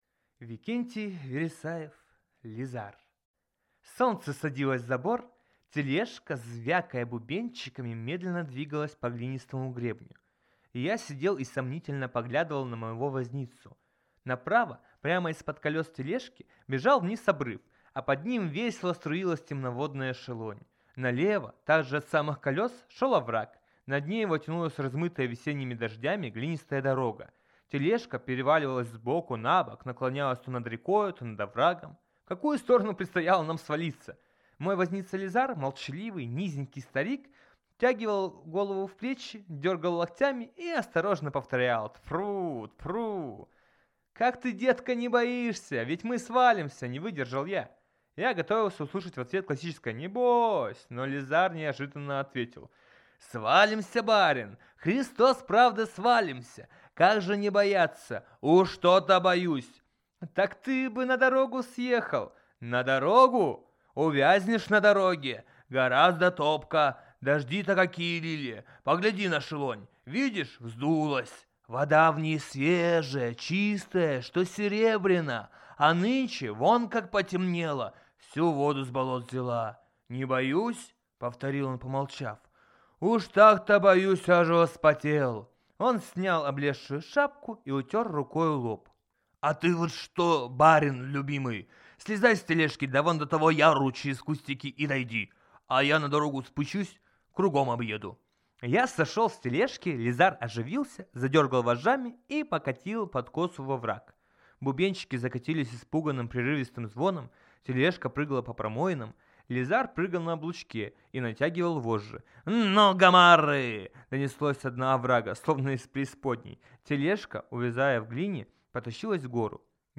Аудиокнига Лизар | Библиотека аудиокниг